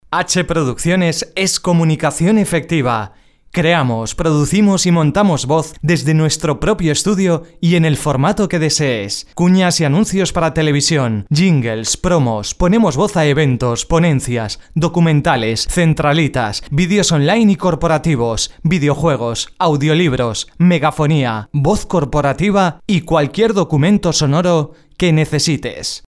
Sprechprobe: Industrie (Muttersprache):
Durante 25 años en el mundo de la radio mi voz ha pasado por tooodos los registros que te puedas imaginar, los más serios, divertidos, tensos, tontos... todo ello plasmado en cuñas de radio, spots de Tv, programas musicales, de noticias, como reportero en unidad móvil, en presentación de eventos y charlas, en definitiva una voz versátil preparada para ser modulada y adaptada a cualquier trabajo